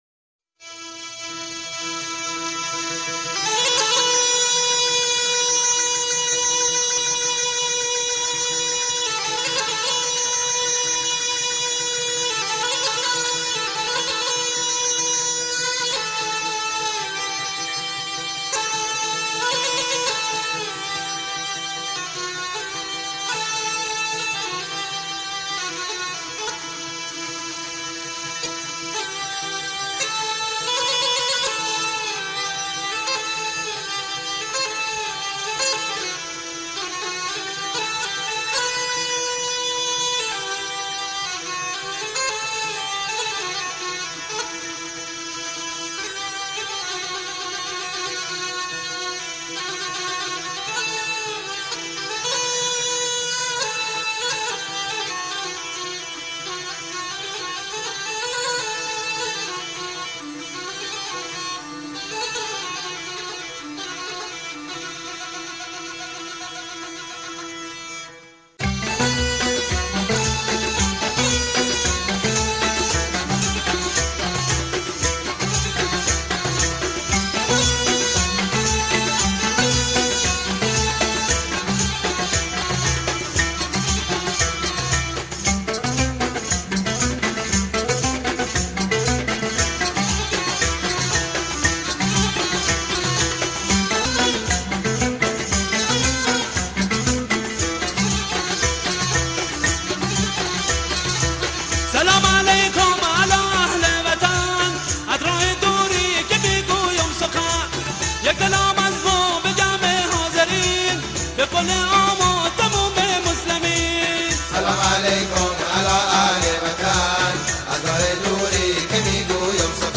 آهنگ جنوبی و بندری شاد